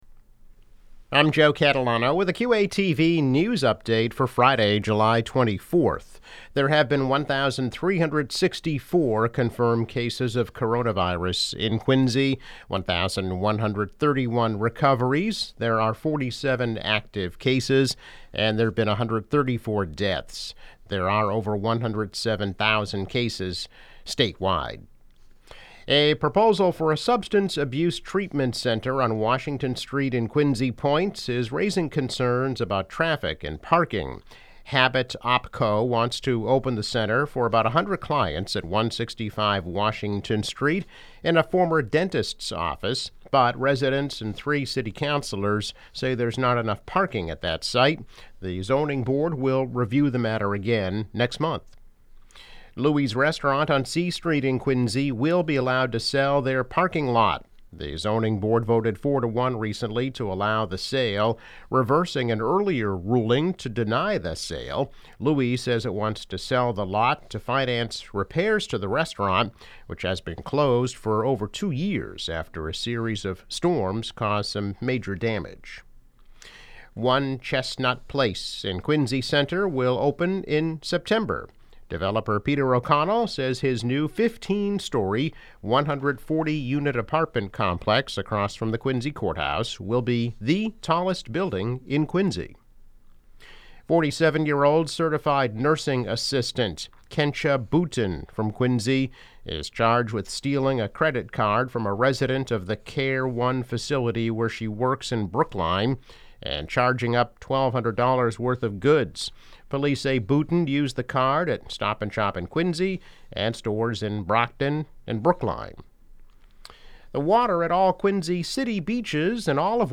News Update - July 24, 2020